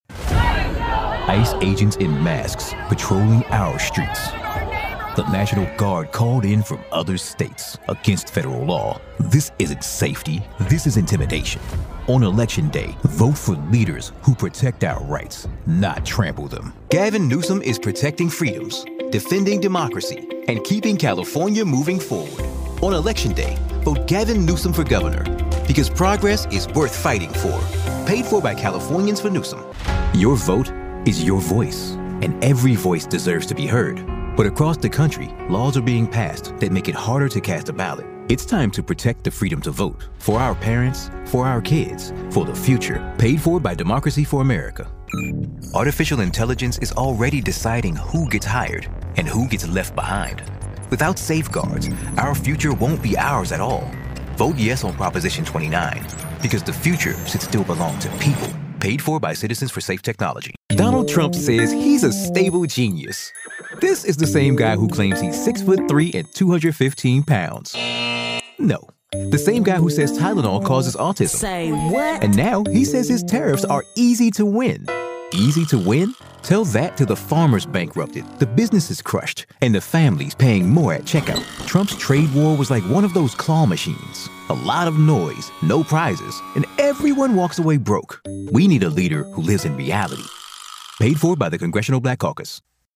Confiado
Dominante
Bien informado